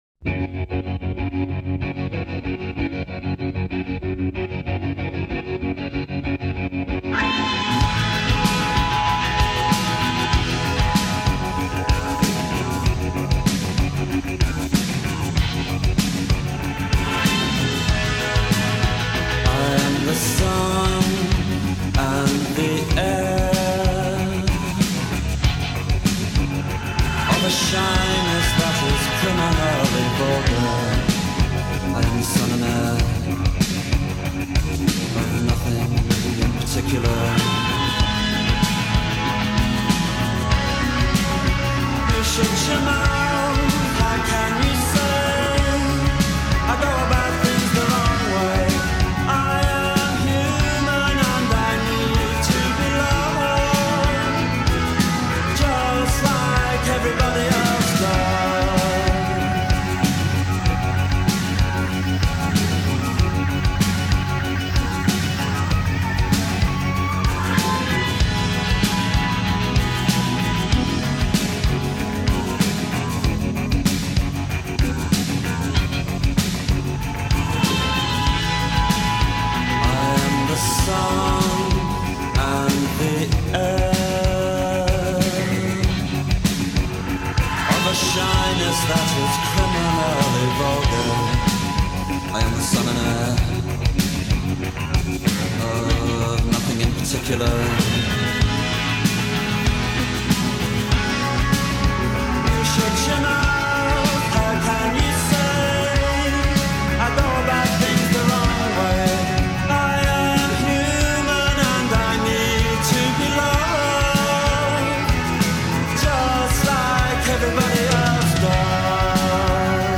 The combination of guitar sounds
The layering.